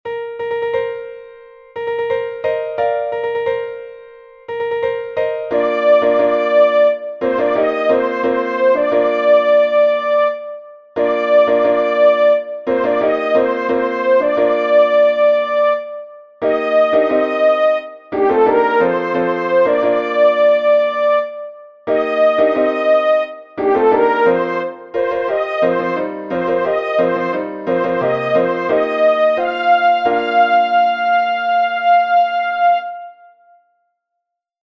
SOPRANO 1
processional-fanfare-sop1.mp3